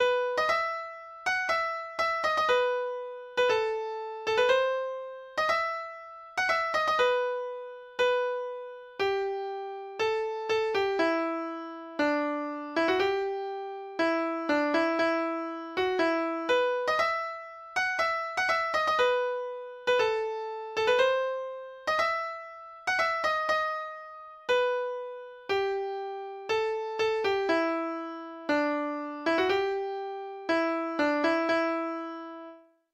Nystev frå Setesdal
Lytt til data-generert lydfil